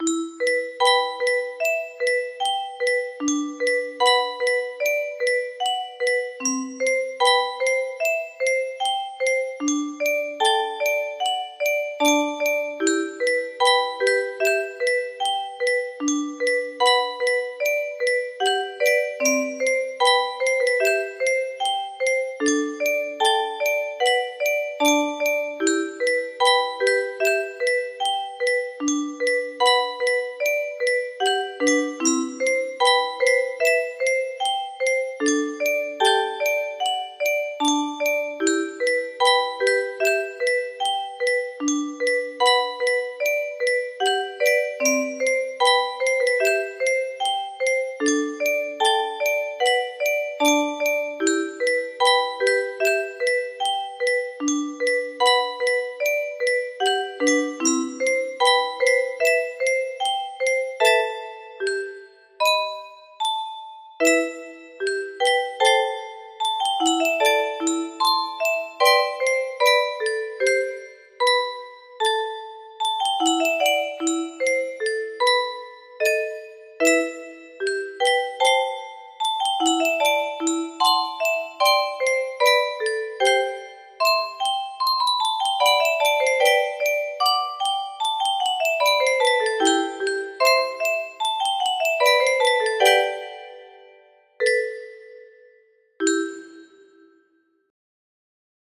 Grand Illusions 30 (F scale)
Kinda wonky but I think it works.